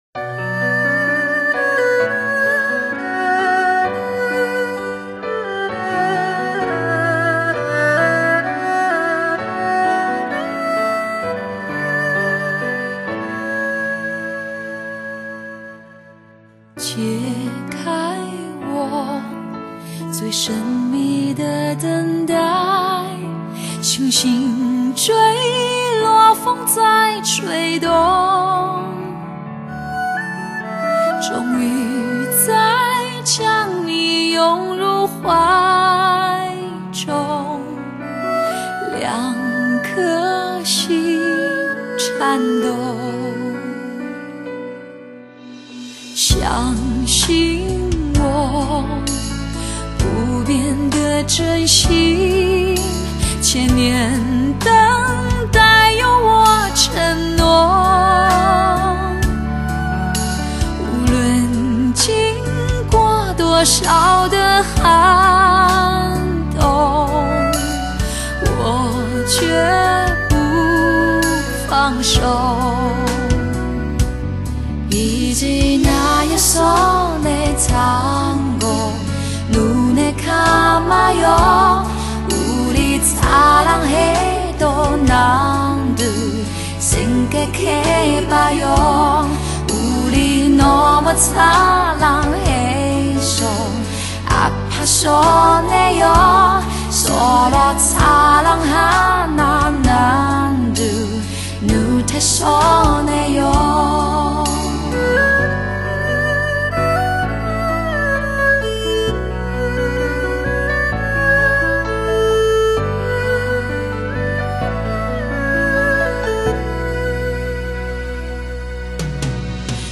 的人”、“飘摇”……浪漫唯美的音乐，回肠荡谷的爱情故事